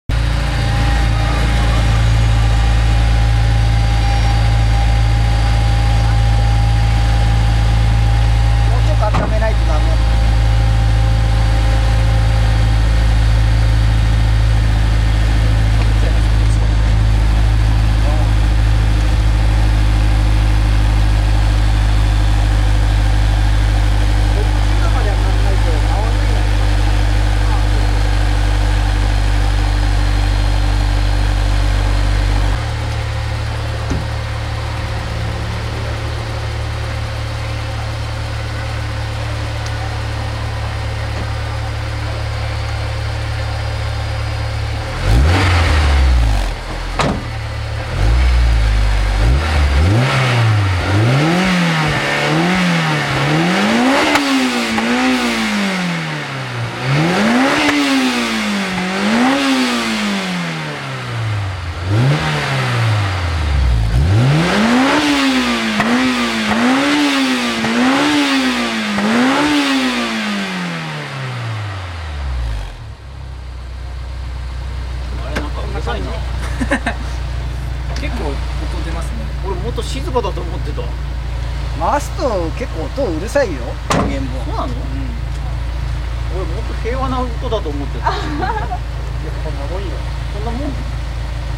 (今回はS2000マフラーサウンド録音オフだよ〜）
マイク：業務用ガンマイク
『※ぴんぽ〜ん 　今回は空ぶかしメインで〜す(^◇^;)』